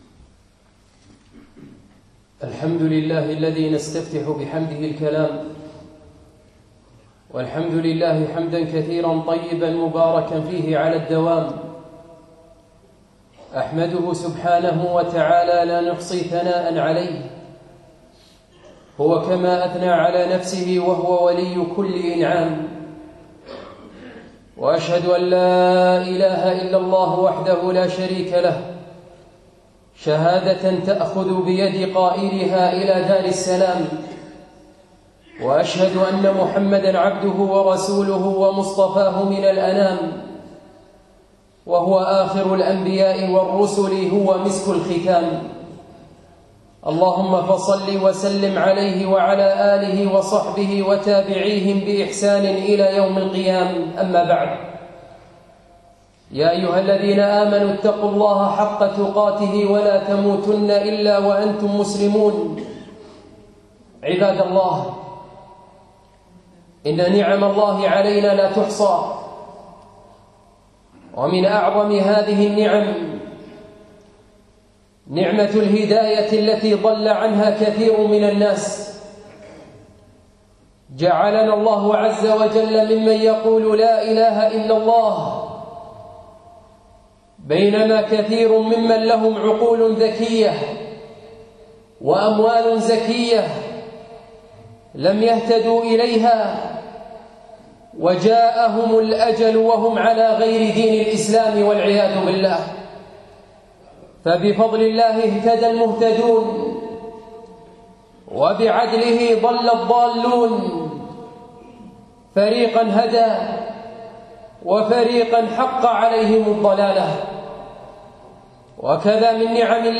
يوم الجمعة 26 ربيع الثاني 1437 الموافق 5 2 2016 مسجد العلاء بن عقبة الفردوس